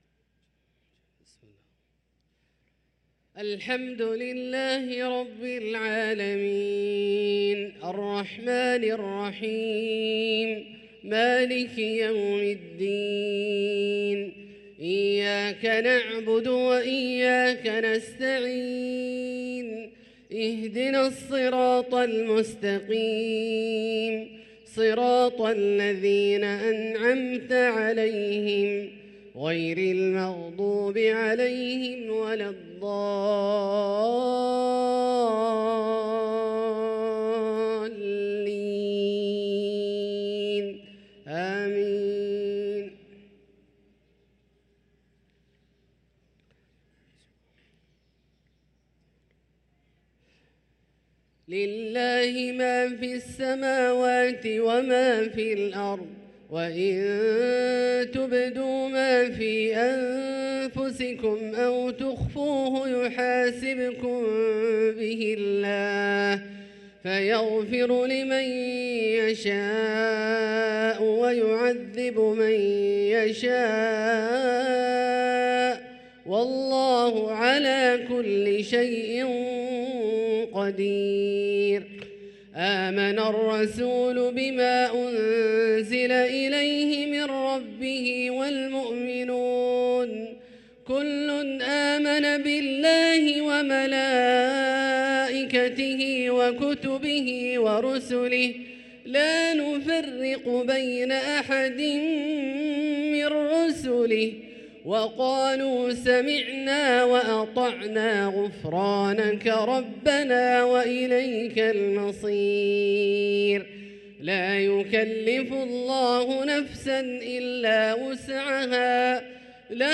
صلاة المغرب للقارئ عبدالله الجهني 14 ربيع الأول 1445 هـ